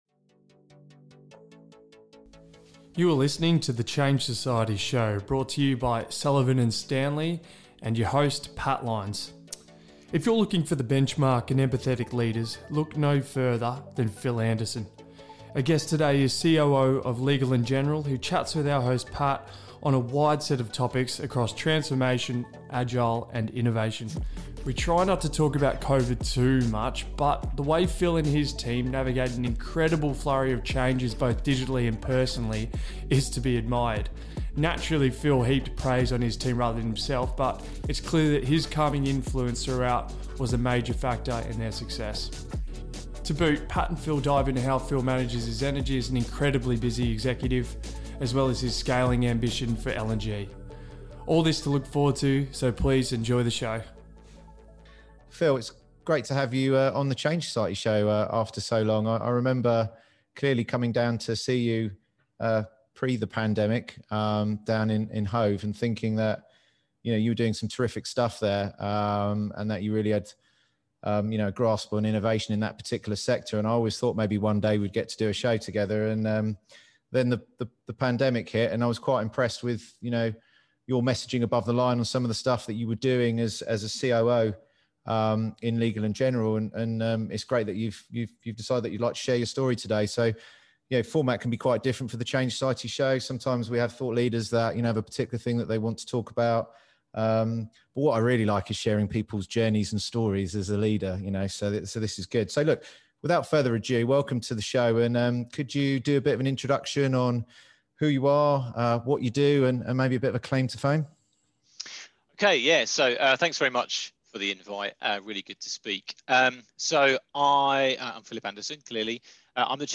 A series of conversations with senior executives and change leaders, discussing the future of work and business innovation.